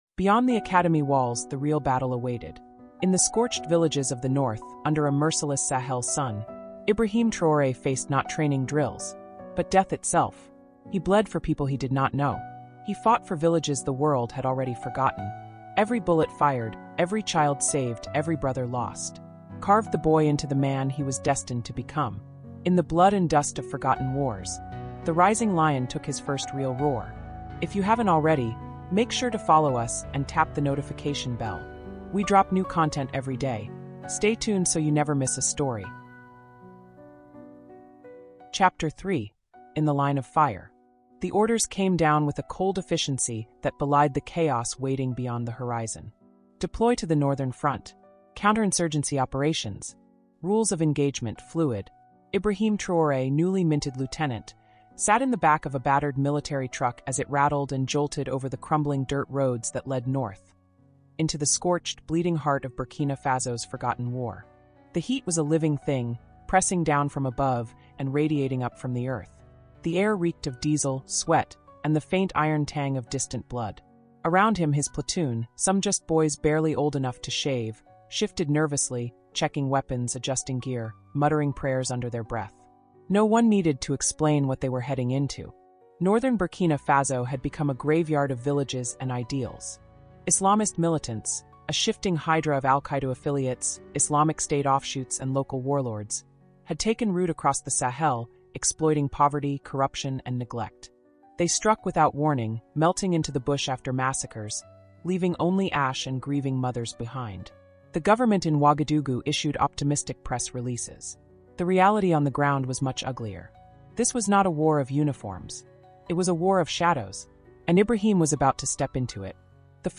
Audiobook for Black history, empowerment, and courage.